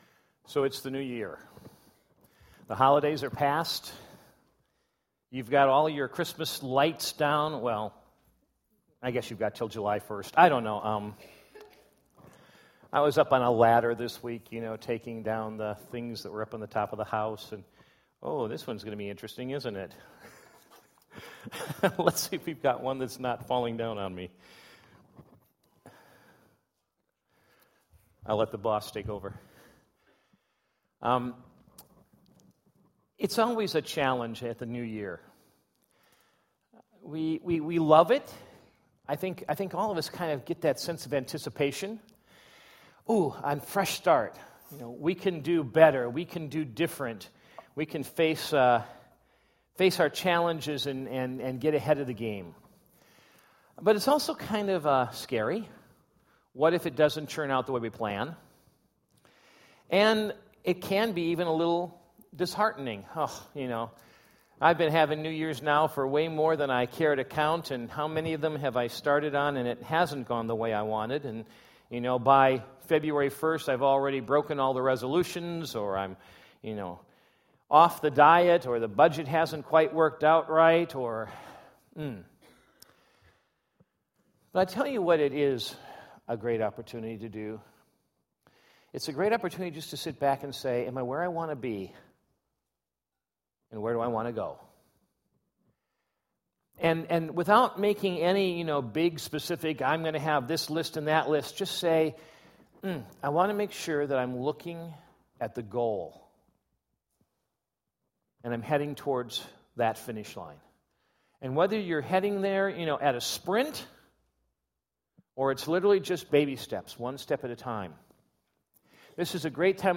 2017 Categories Sunday Morning Message Download Audio Ministry Priorities